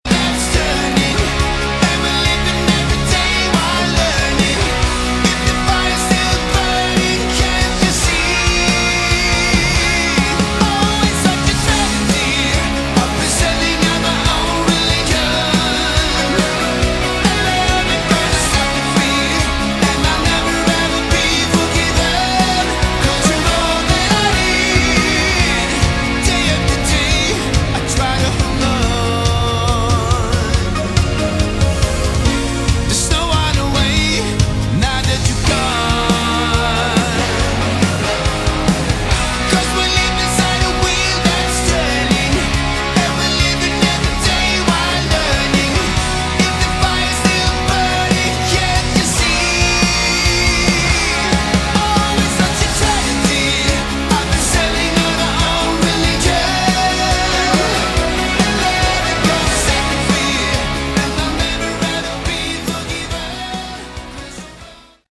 Category: Hard Rock
lead vocals
bass
guitars
drums